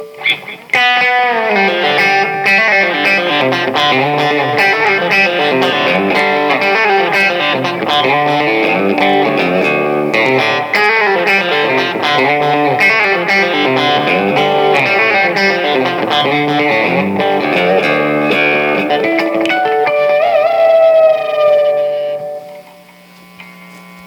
This is the lead that comes in around 1:00.